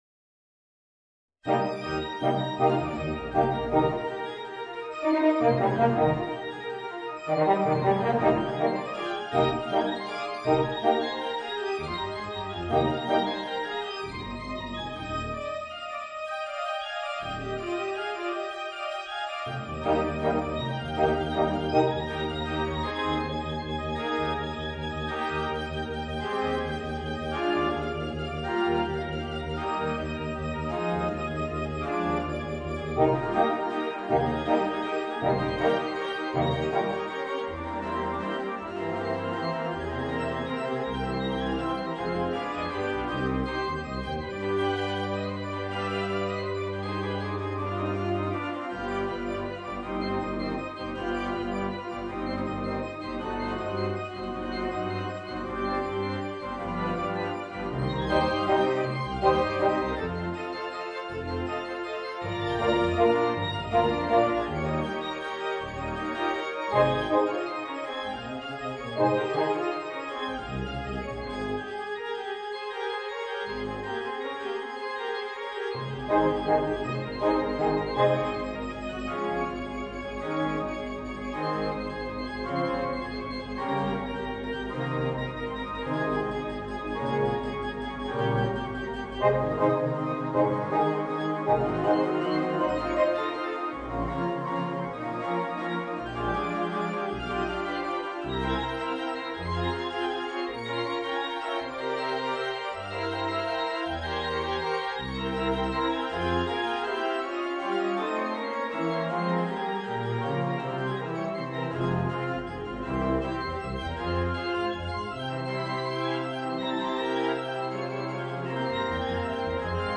Voicing: 3 Eb Horns and Organ